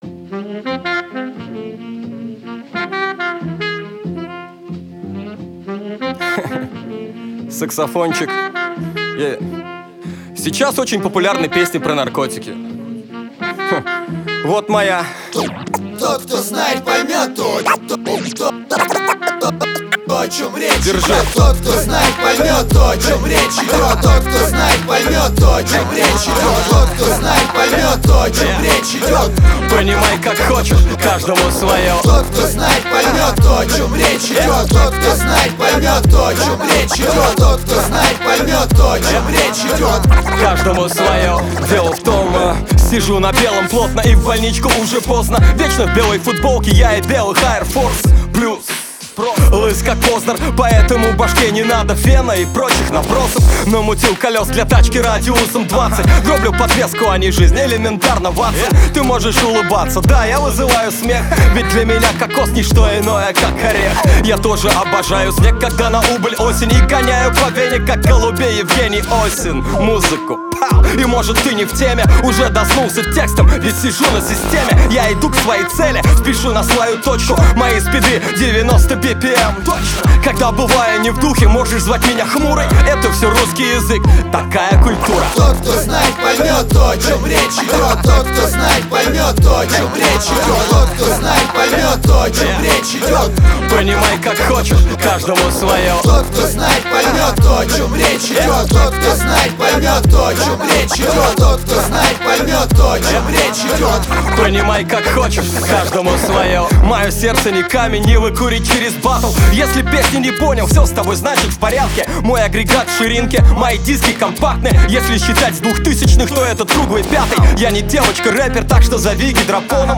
Жанр: Рэп (Хип-хоп)